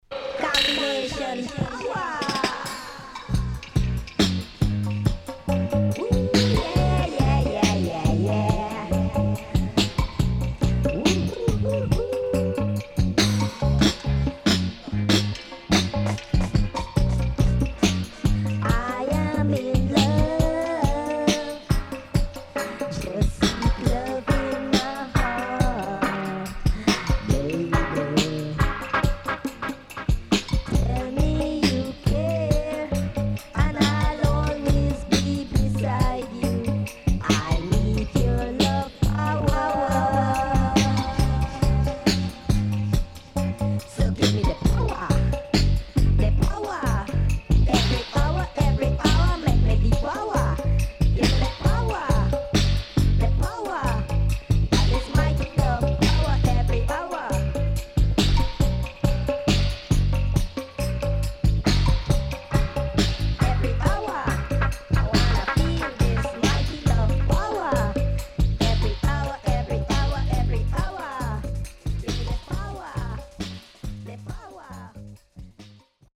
HOME > DUB
SIDE A:A-1序盤ジリジリしたノイズありますがそれ以降ノイズ少ないです。